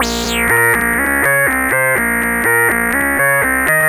Daft Lead B 123.wav